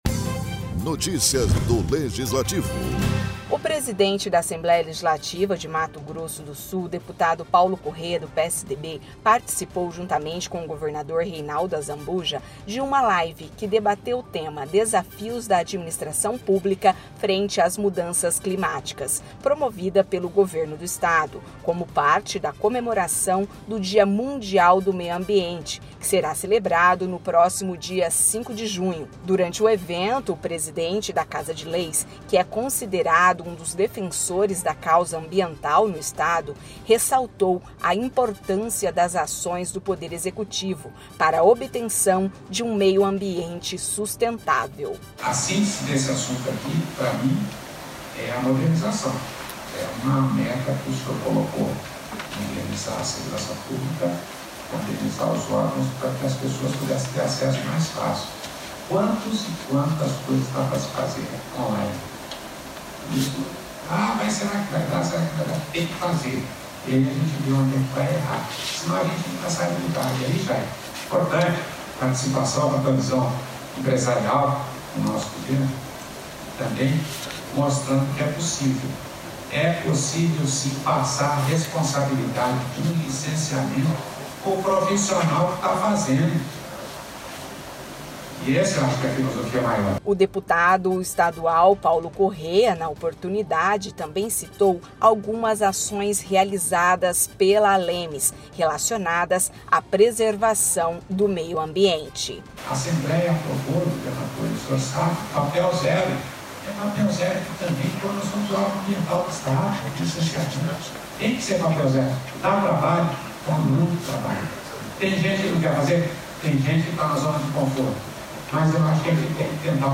O presidente da Assembleia Legislativa de Mato Grosso do Sul (ALEMS) deputado Paulo Corrêa (PSDB), participou juntamente com o governador Reinaldo Azambuja (PSDB), de uma live que debateu o tema, “Desafios da Administração Pública frente às Mudanças Climáticas”, promovida pelo Governo do Estado, como parte da comemoração ao Dia Mundial do Meio Ambiente, celebrado no próximo dia 5 de junho.